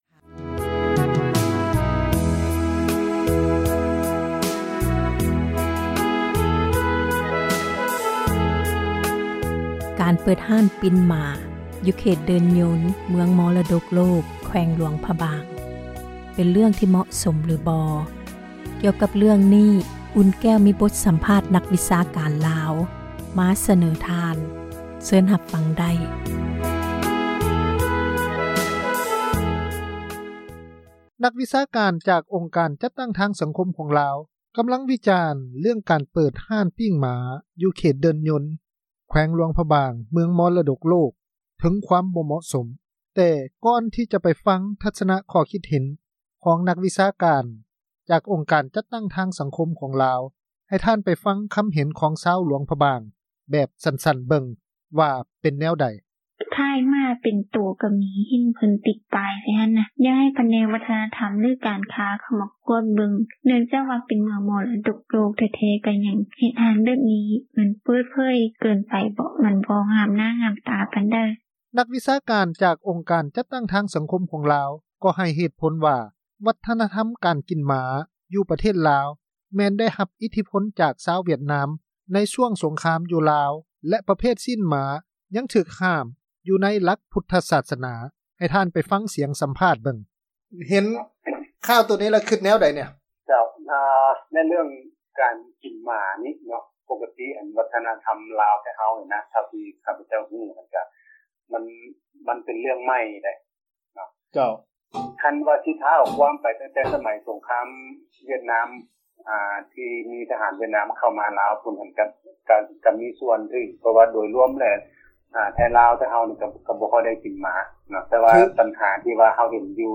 ສຳພາດ ນັກວິຊາການ ລາວ